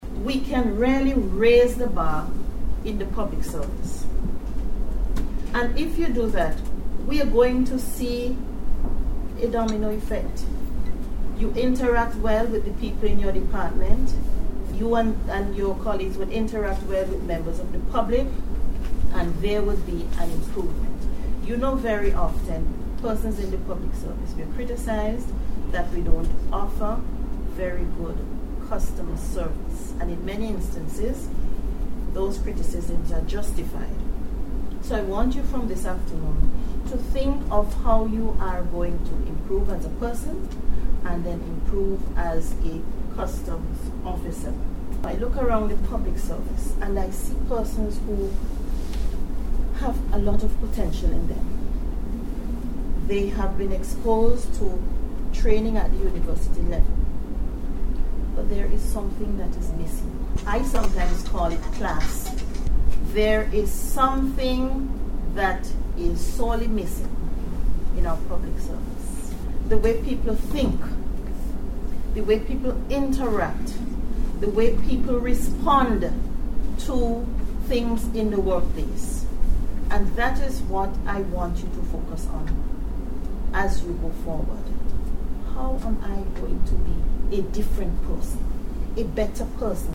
Corriene Gonsalves At The Jr. Customs Officers Training Programme Graduation
Chief Personnel Officer, Corriene Gonsalves while addressing graduates of a Jr Customs Officers training programme on Monday said that things can be changed to make an improved public service, and those changes will spark a chain reaction.